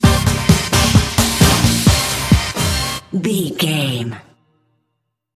Fast paced
Ionian/Major
Fast
synthesiser
drum machine
Eurodance